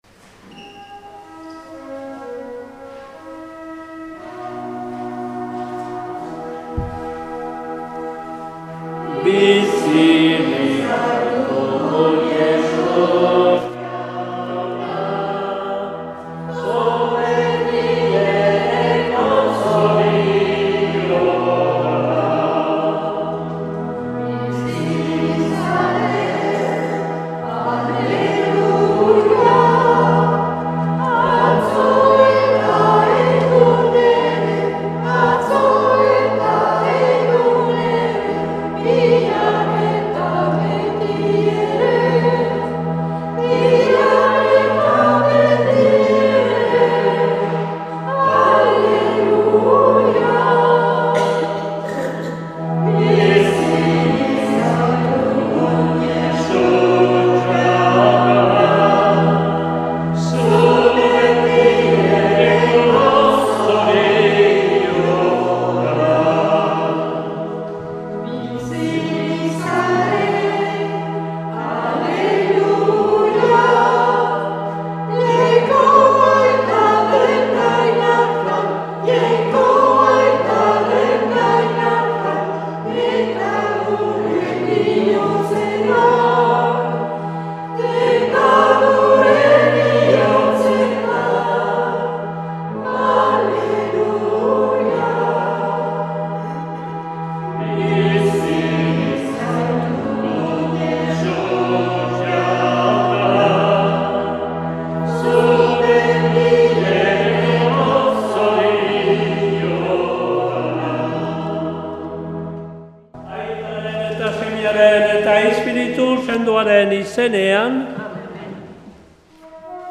2023-10-29 Urteko 30. Igandea A - Urepele